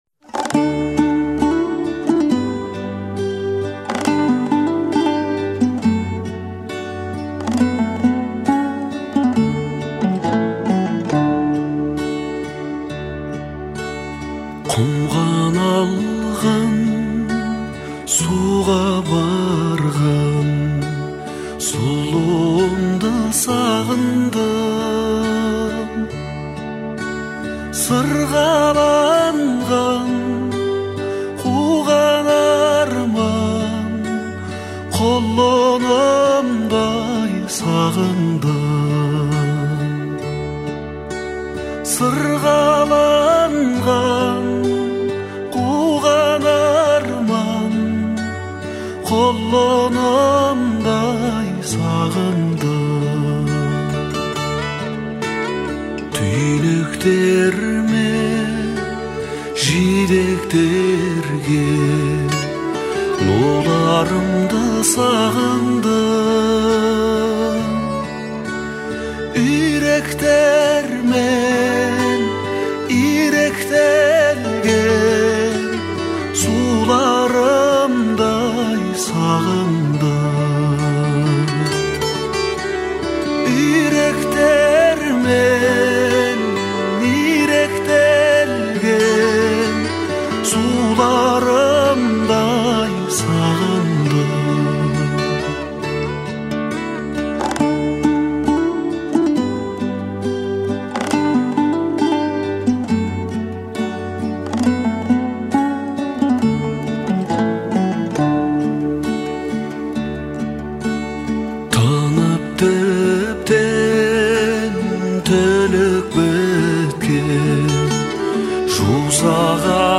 это яркая и эмоциональная песня в жанре казахской поп-музыки